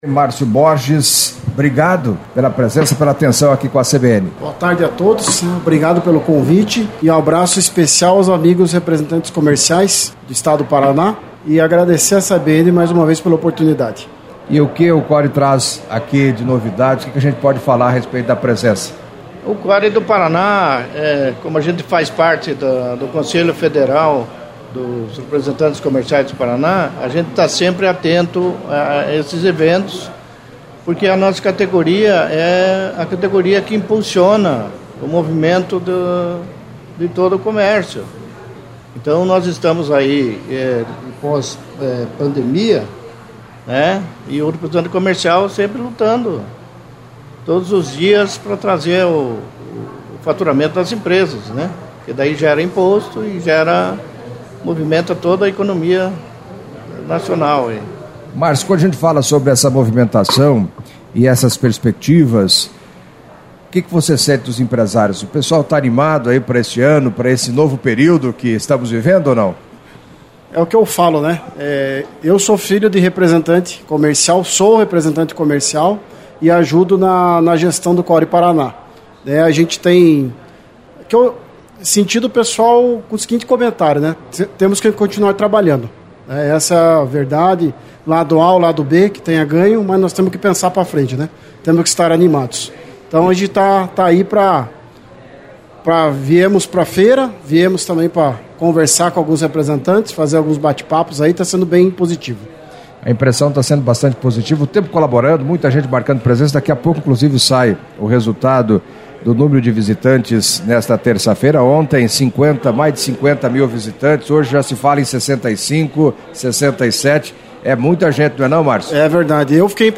Editoriais
Entrevista